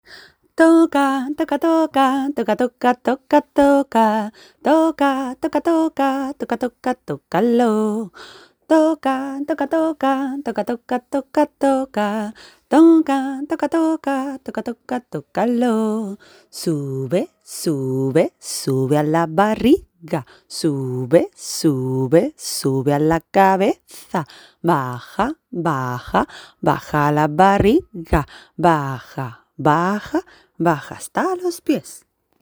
Mayor y doble.